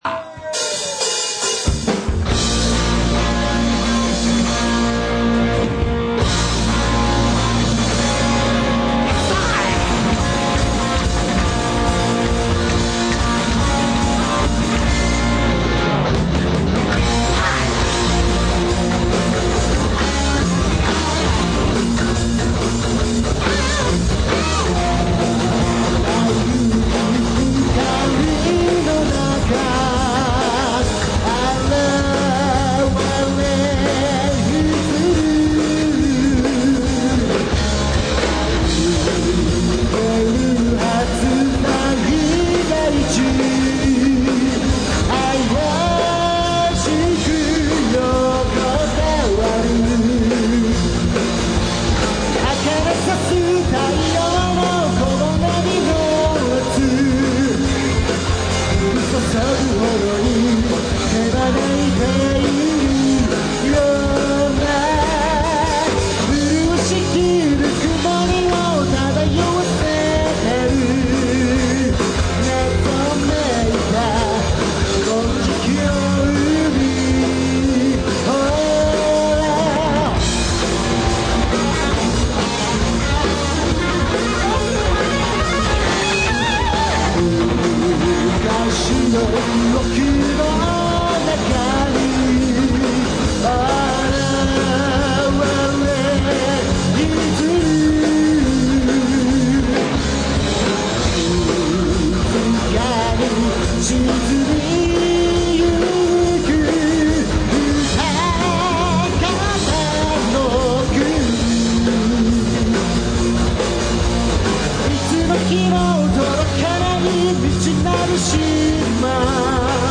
at.大塚 Welcome back!!